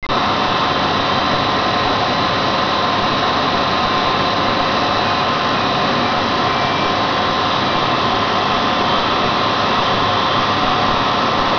In Ihrem Fall werden die Profi's Löcher in den Estrich bohren und entfeuchtete Luft in die Dämmung unter dem Estrich einblasen. Im Raum stehen dann mehrere Entfeuchter sowie ein Gebläse.